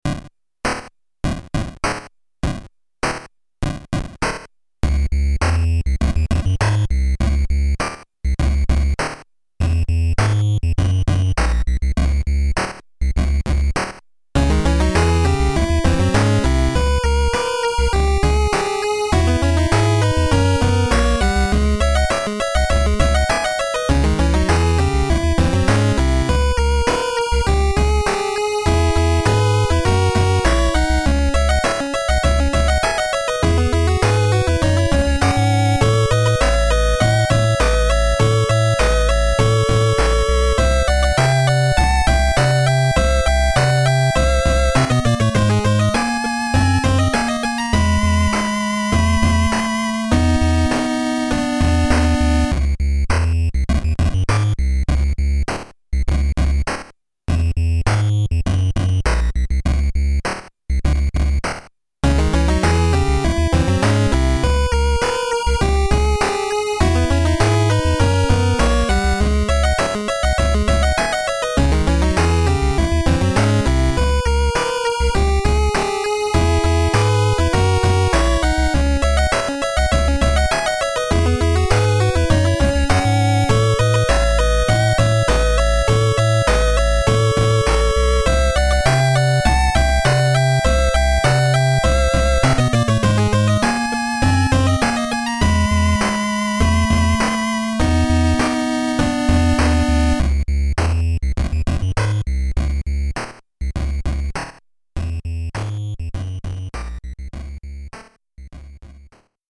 nes
arrangement
retro